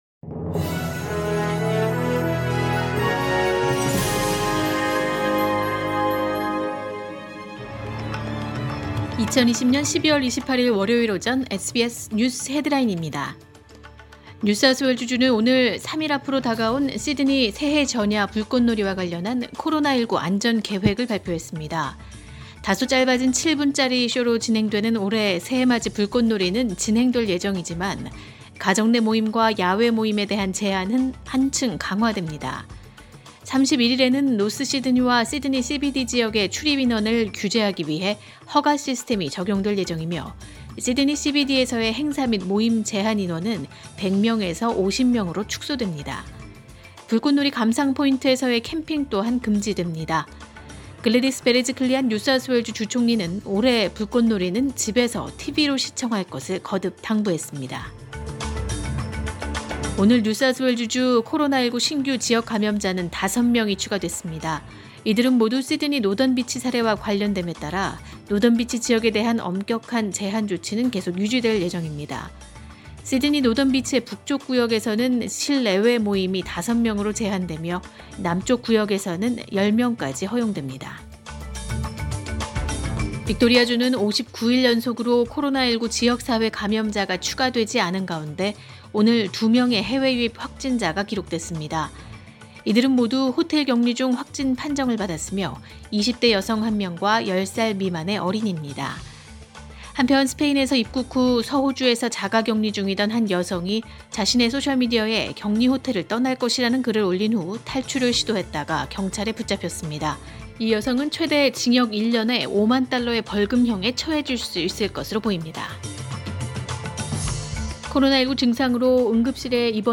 2020년 12월 28일 월요일 오전의 SBS 뉴스 헤드라인입니다.